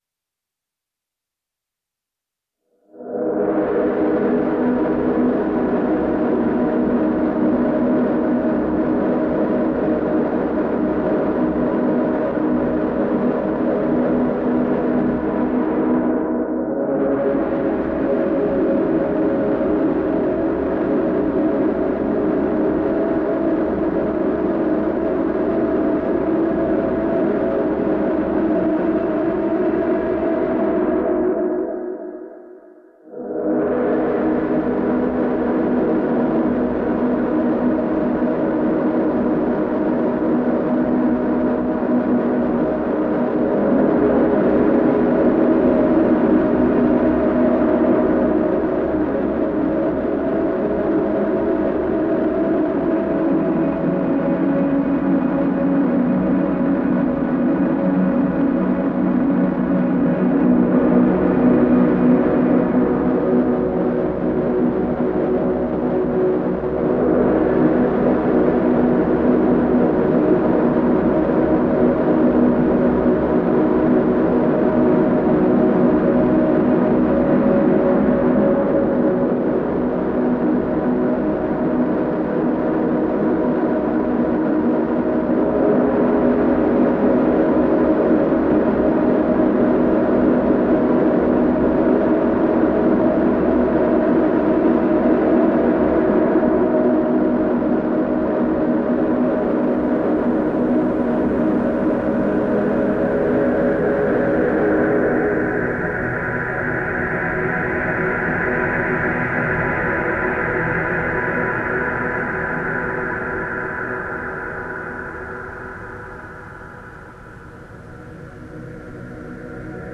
in Oxford, UK